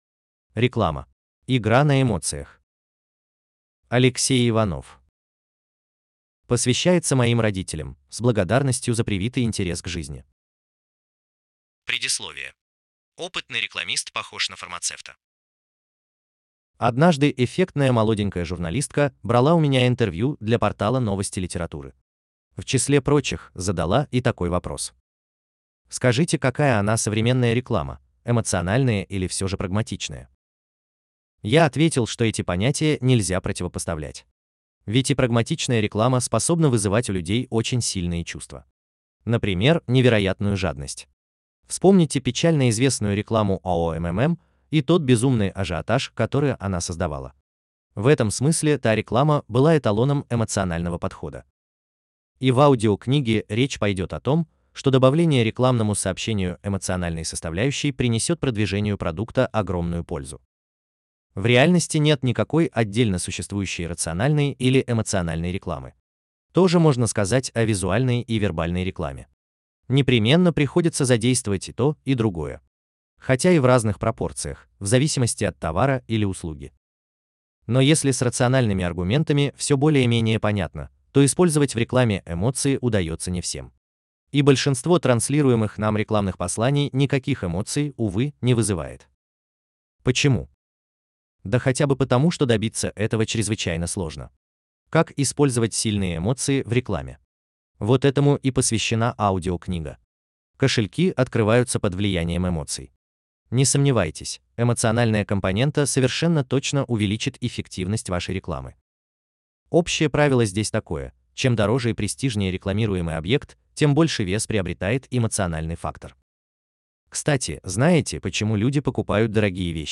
Аудиокнига Реклама. Игра на эмоциях | Библиотека аудиокниг